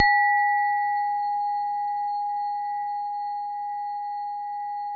WHINE  G#3-R.wav